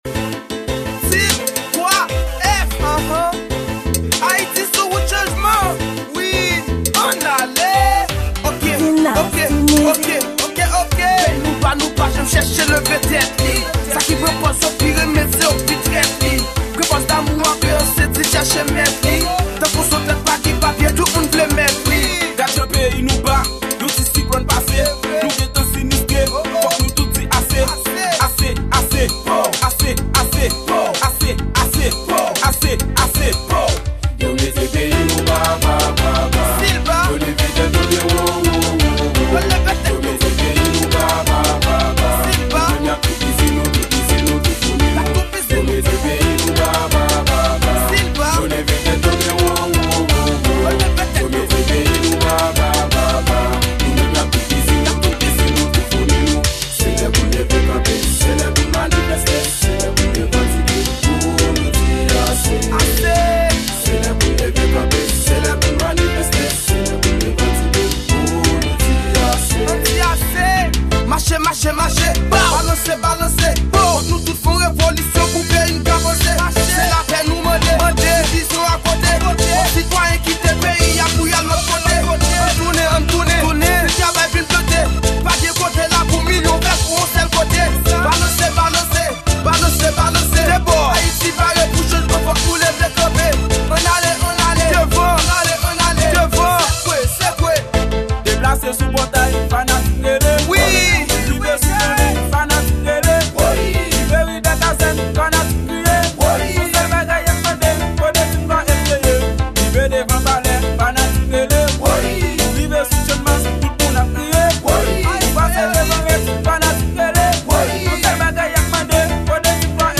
Genre: Kanaval